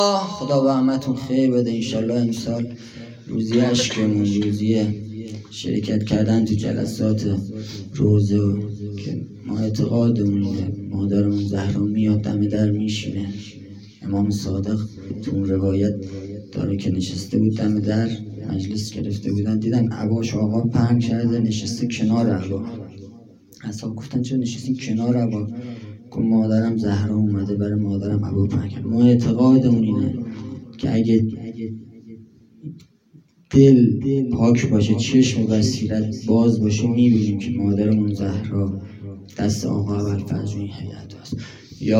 صحبت